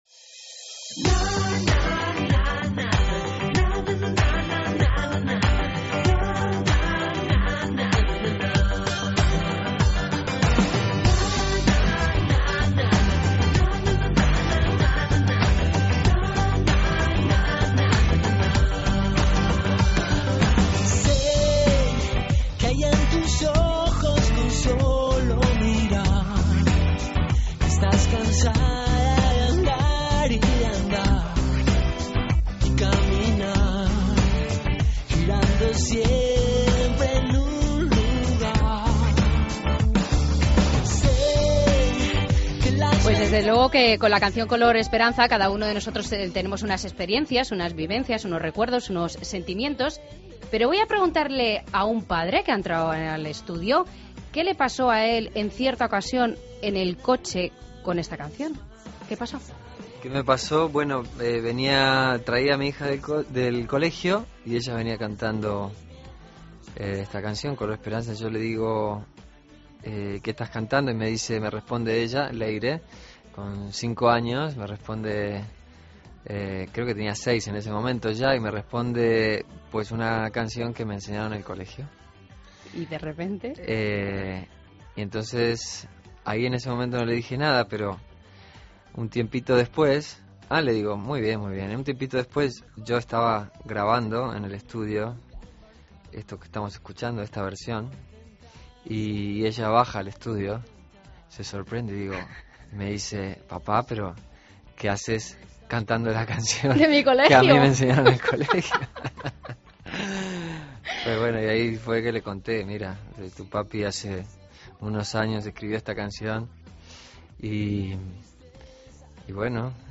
AUDIO: Entrevista a Coti en Fin de Semana COPE